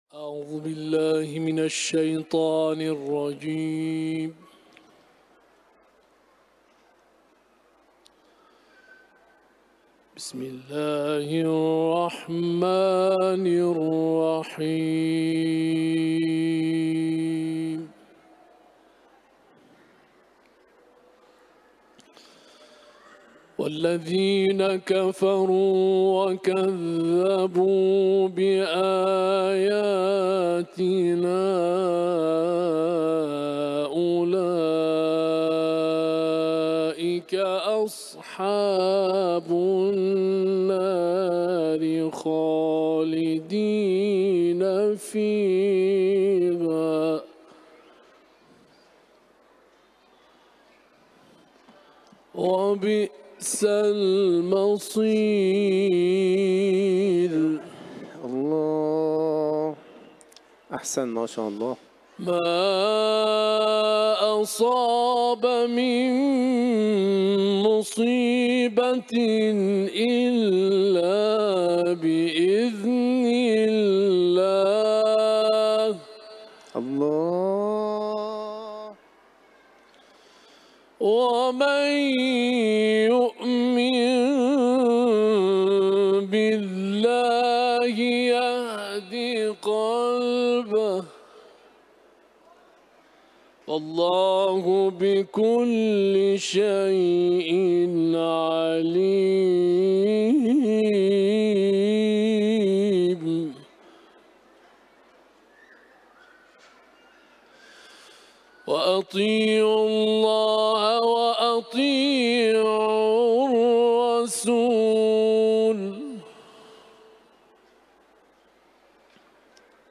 سوره تغابن ، تلاوت قرآن